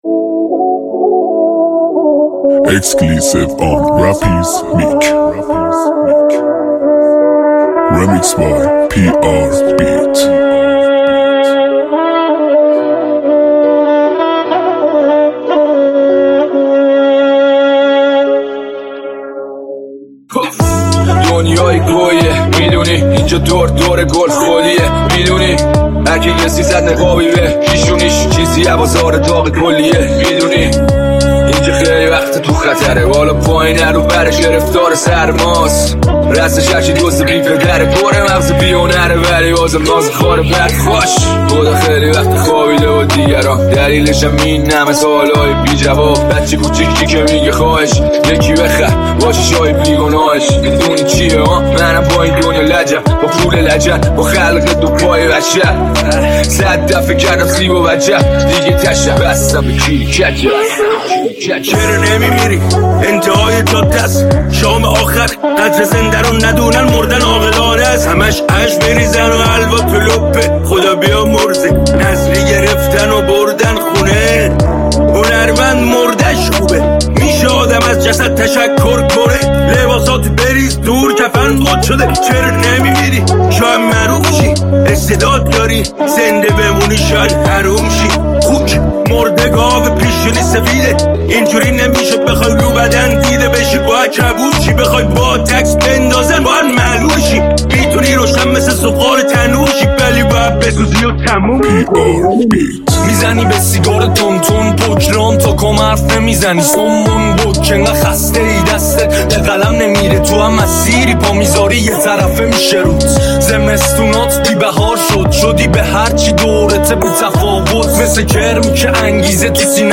کیفیت بده متاسفانه حیفش